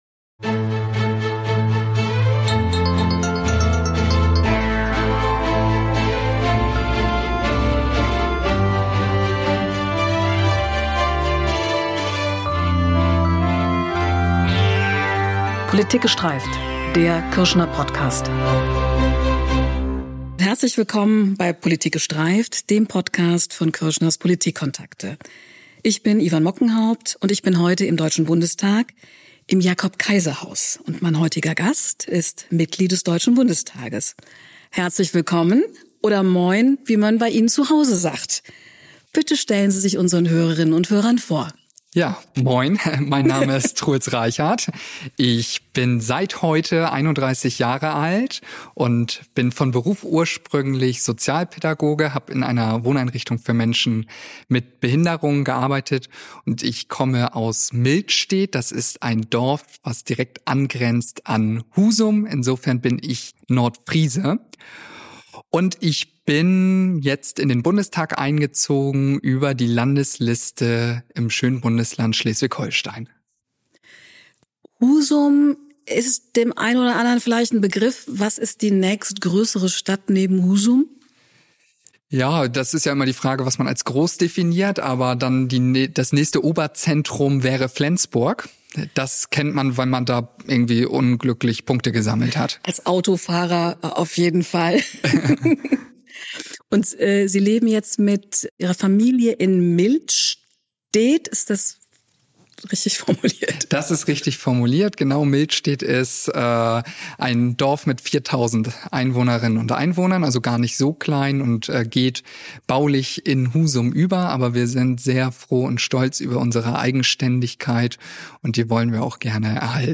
mit dem Abgeordneten Truels Reichardt